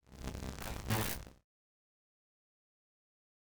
meleeattack-swoosh-magicaleffect-group01-lightning-01.ogg